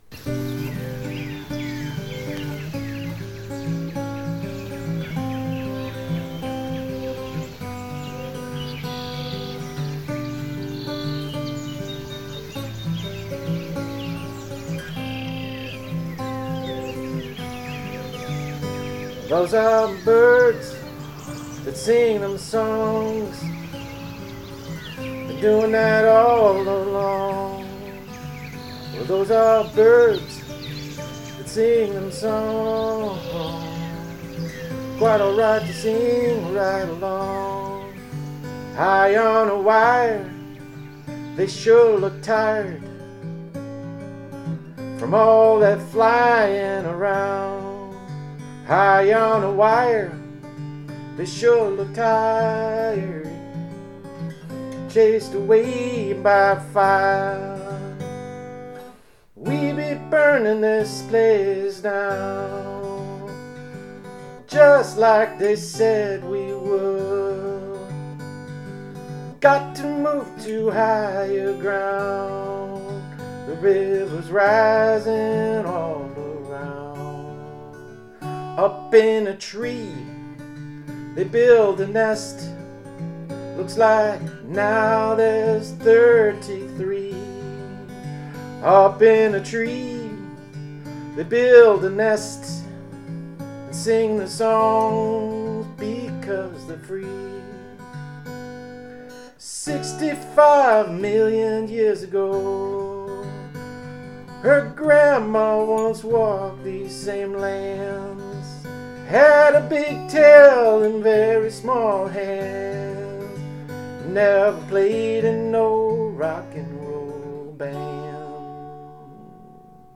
guitar
Bird recording is from the Kickapoo River in Wisconsin at dusk that I made while on a bike tour (there are seven different bird songs).